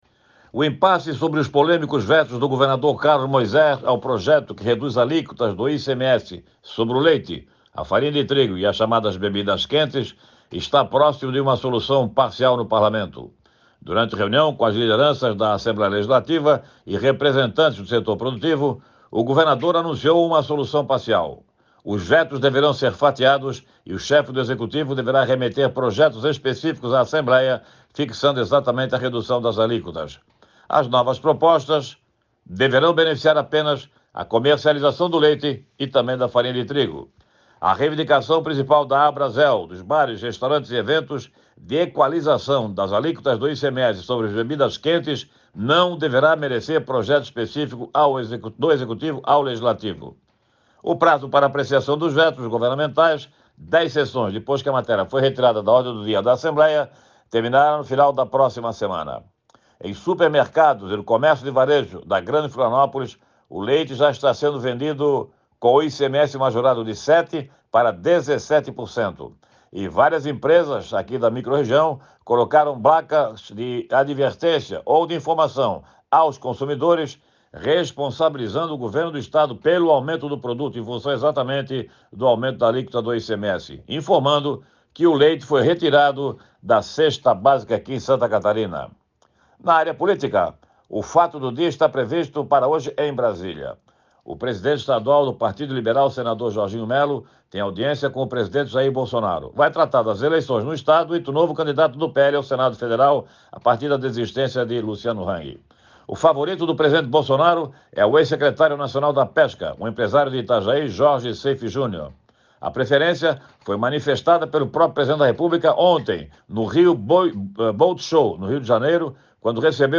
Jornalista destaca reunião do senador Jorginho Mello (PL) com o presidente Jair Bolsonaro e a força das cooperativas catarinenses